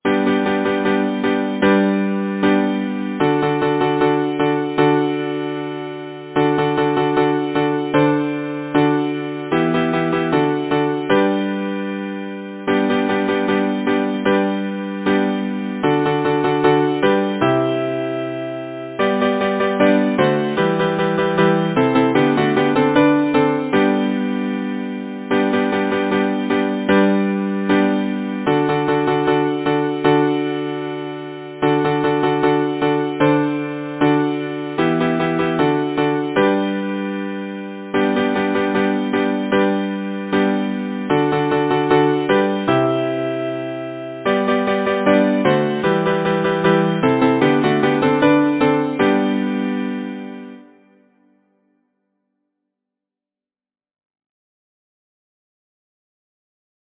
Title: The Honey Bee Composer: William A. Lafferty Lyricist: Number of voices: 4vv Voicing: SATB Genre: Secular, Partsong
Language: English Instruments: A cappella